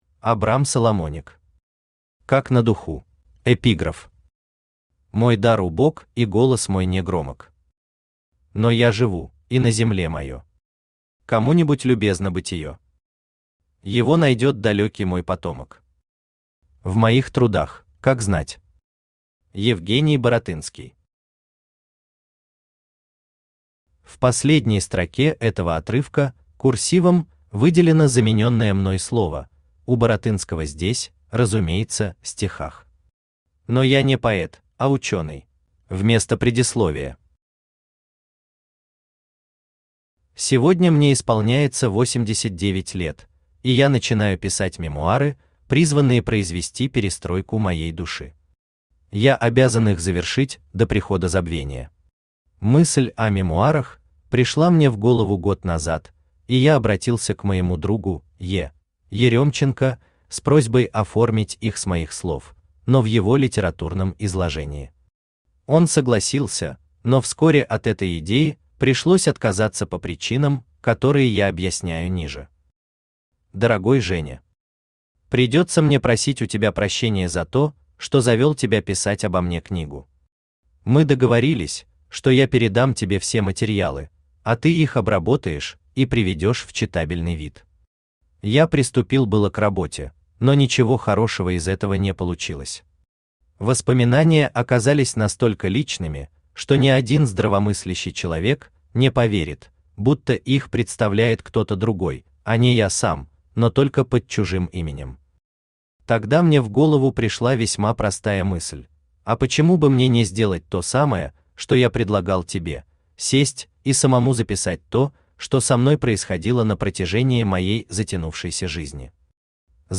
Аудиокнига Как на духу | Библиотека аудиокниг
Aудиокнига Как на духу Автор Абрам Бенцианович Соломоник Читает аудиокнигу Авточтец ЛитРес.